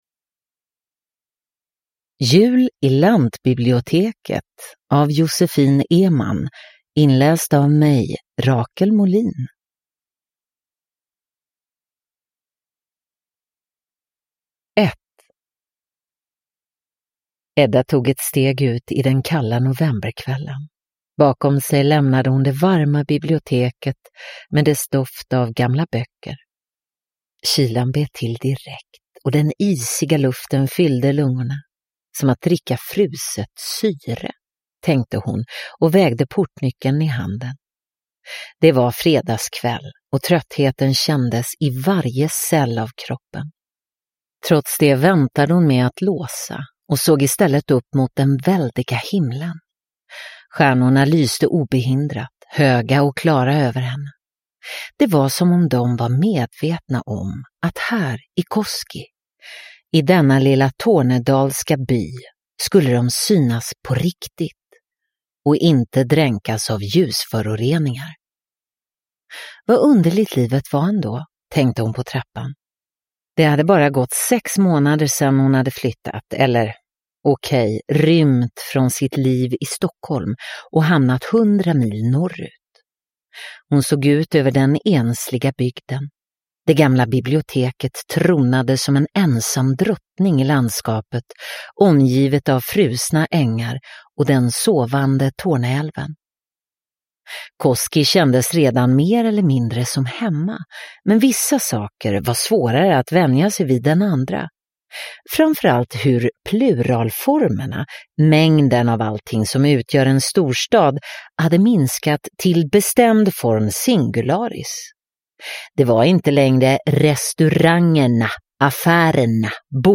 Jul i lantbiblioteket – Ljudbok – Laddas ner